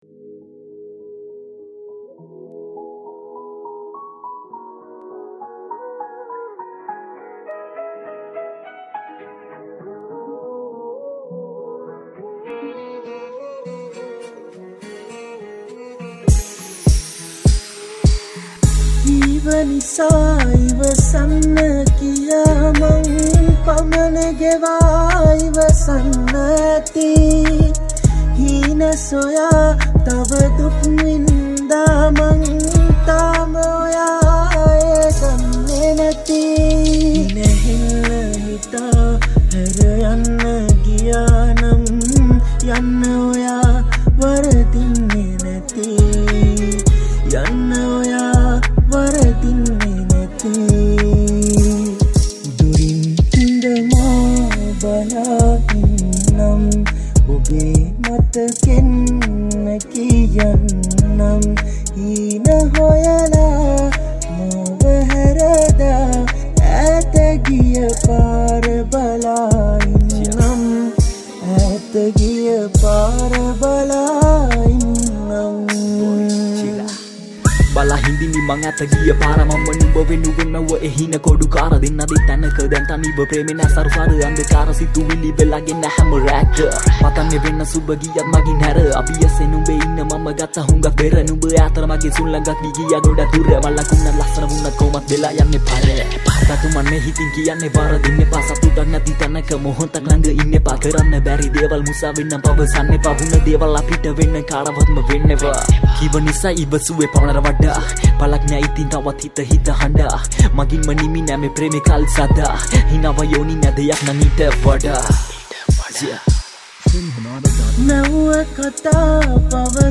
Rap Songs
Category: Rap Songs